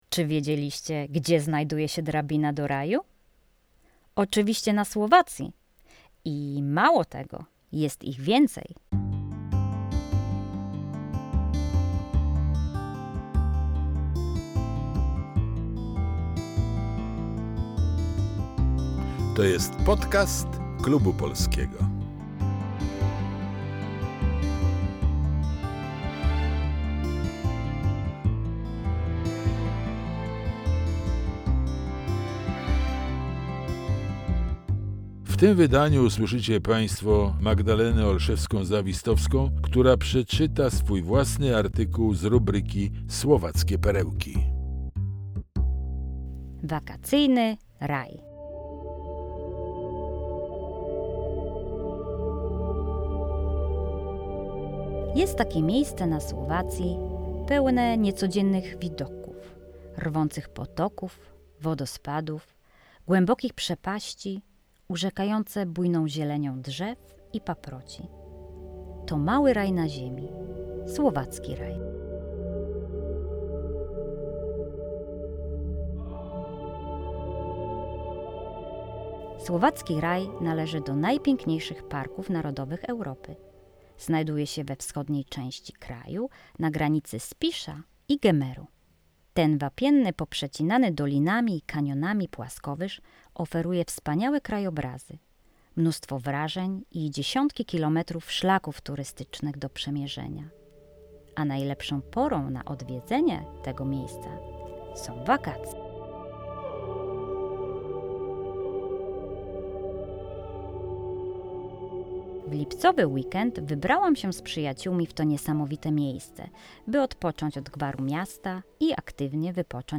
Czyta
Podkład muzyczny i dźwięk